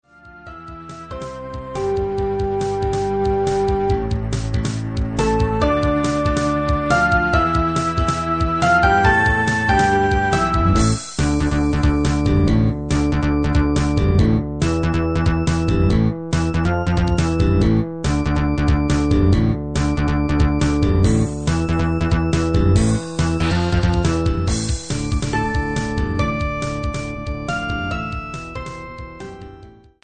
BPM=140
あとはその直後、Bメロのギターがアジアン？
オリジナル 暗い 早い